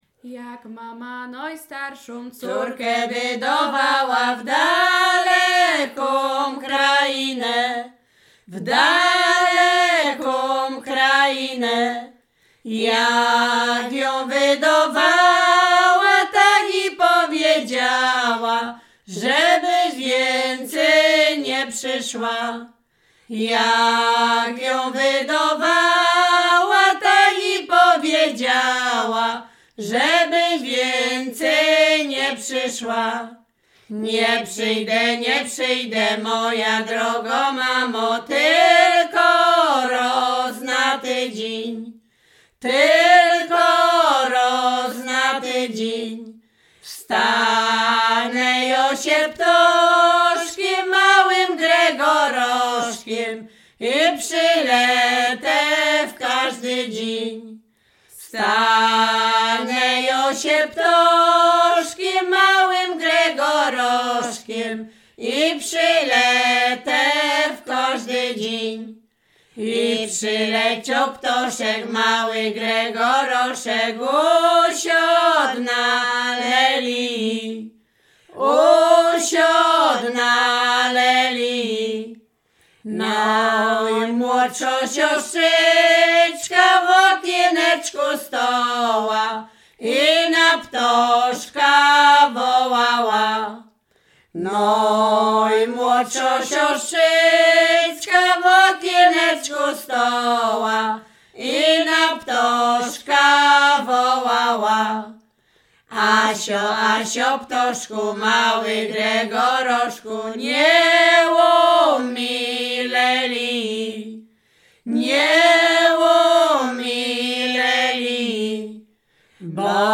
Śpiewaczki z Mroczek Małych
województwo łodzkie, powiat sieradzki, gmina Błaszki, wieś Mroczki Małe
liryczne miłosne weselne